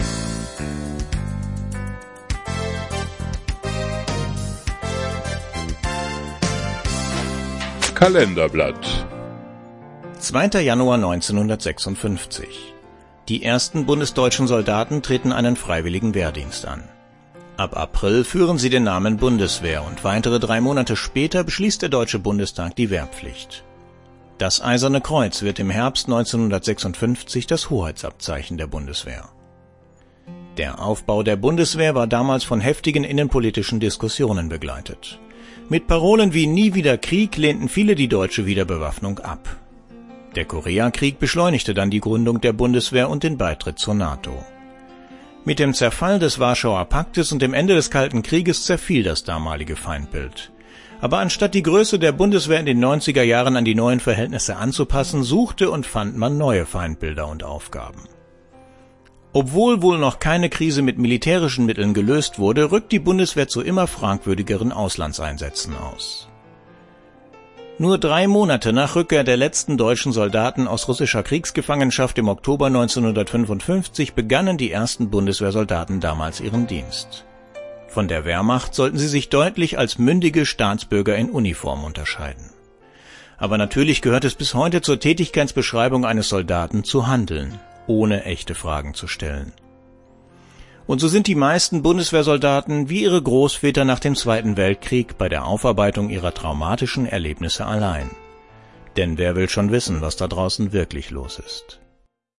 Sprecher: